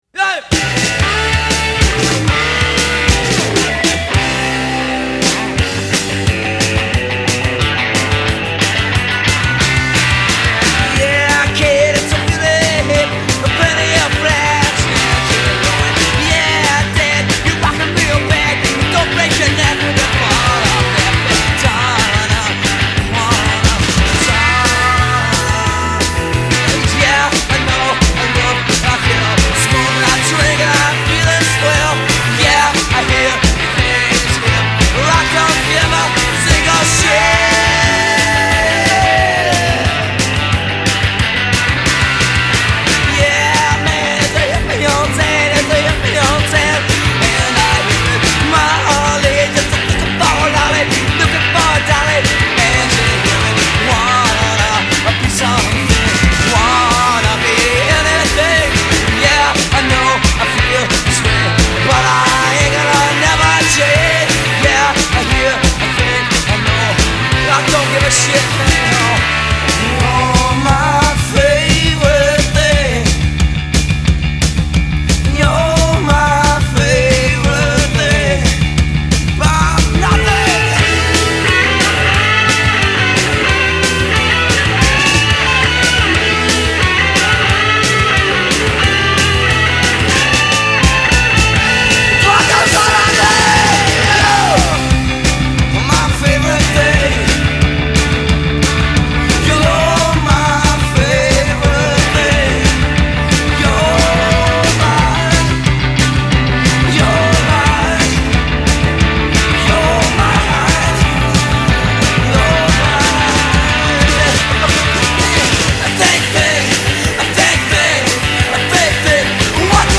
Side one still had flares of the 80s punk/hardcore roots.